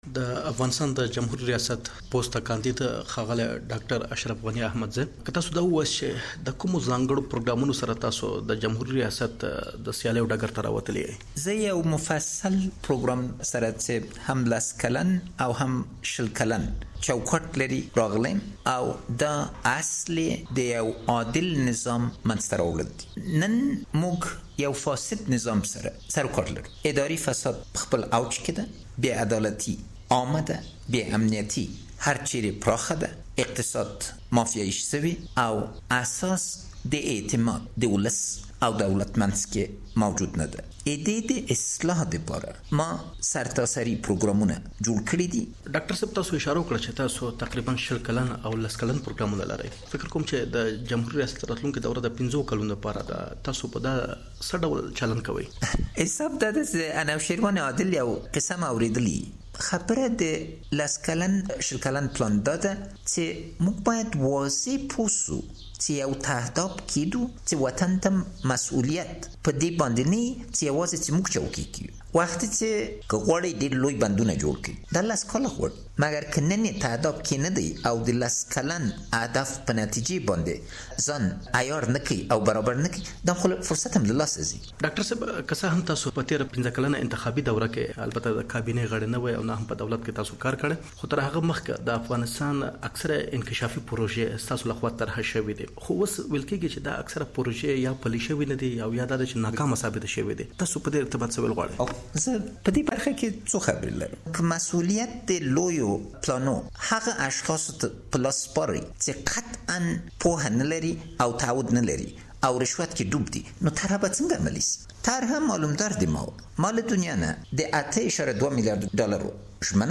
له اشرف غني احمدزي سره ځانګړې مرکه واورﺉ